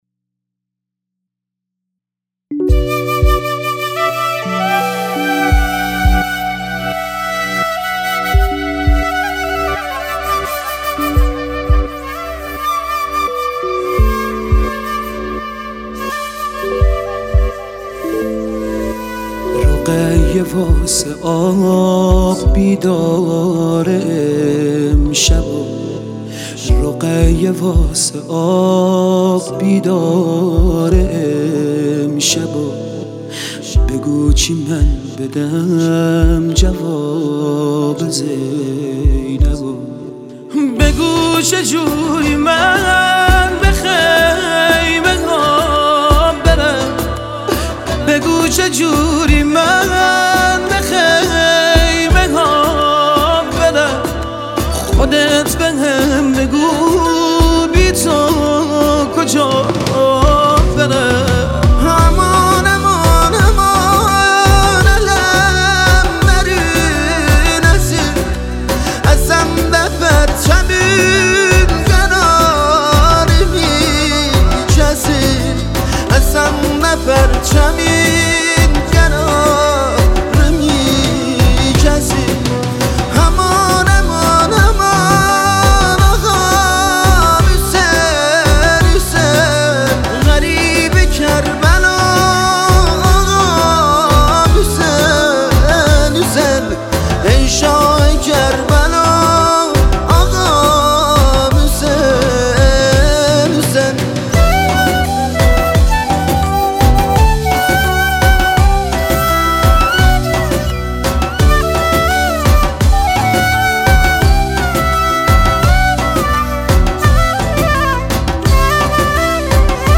مذهبی و نوحه